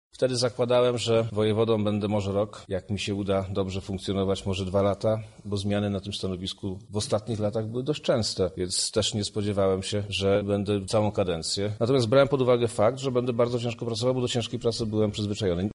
Była to dla mnie  całkowicie nowa sytuacja tak o swojej kończącej się kadencji mówi  Przemysław Czarnek, Wojewoda Lubelski